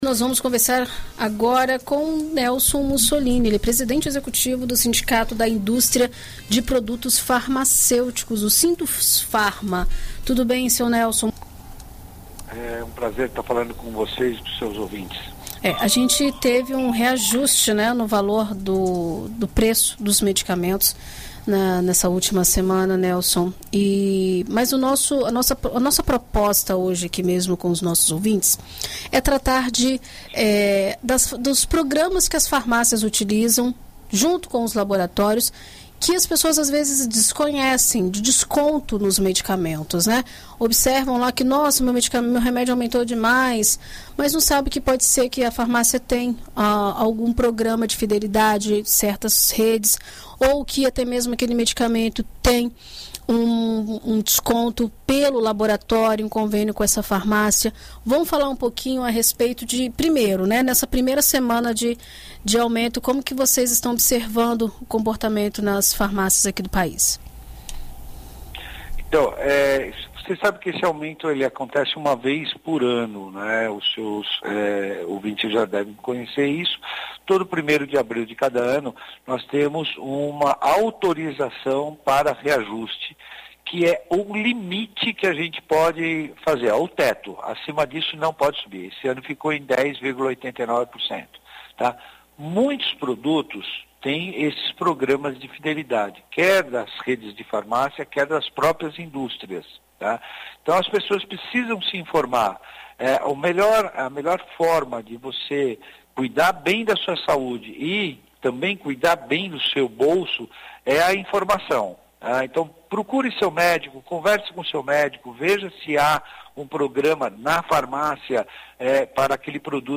Em entrevista à BandNews FM Espírito Santo nesta sexta-feira